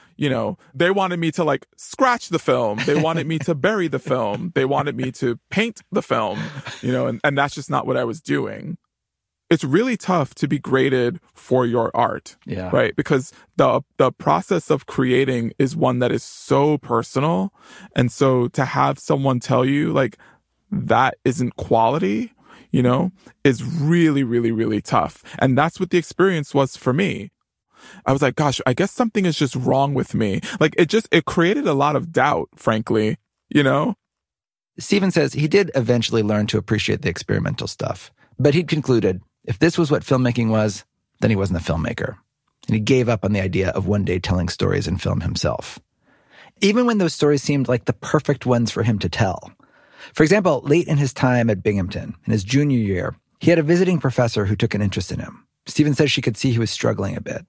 Play Rate Listened List Bookmark Get this podcast via API From The Podcast 1 8 Candid conversations with entrepreneurs, artists, athletes, visionaries of all kinds—about their successes, and their failures, and what they learned from both. Hosted by Alex Blumberg, from Gimlet Media.